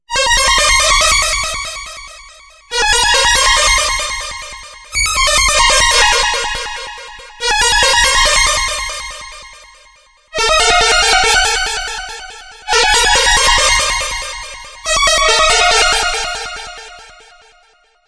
Synth: abused LFO
synth-lfo.mp3